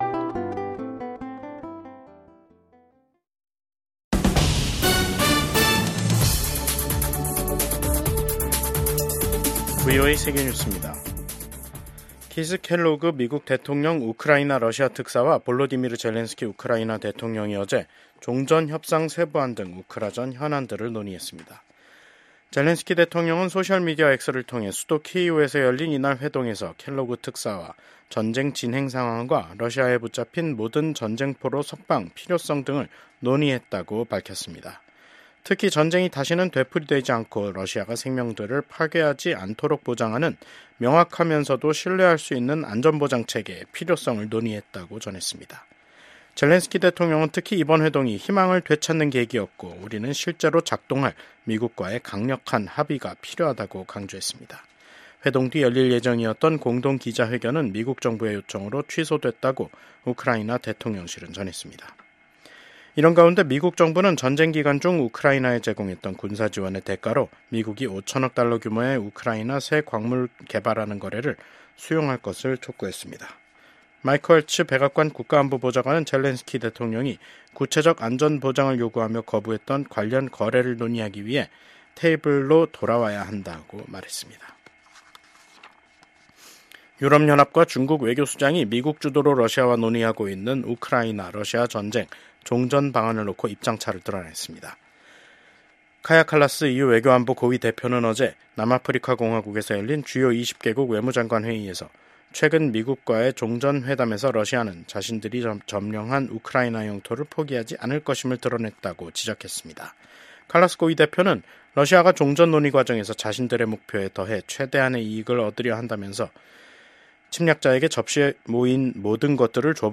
VOA 한국어 간판 뉴스 프로그램 '뉴스 투데이', 2025년 2월 21일 2부 방송입니다. 미국 백악관 국가안보보좌관은 도널드 트럼프 대통령이 김정은 북한 국무위원장을 비롯한 독재자들에 맞설 수 있는 유일한 인물이라고 밝혔습니다. 러시아에 파병된 북한군의 전투력이 낮아 우크라이나 군에 압도당하고 있다고 전 유럽주둔 미군 사령관이 평가한 가운데 유엔 인권기구는 우크라이나군에 생포된 러시아 파병 북한군을 본국으로 돌려보내서는 안 된다는 입장을 밝혔습니다.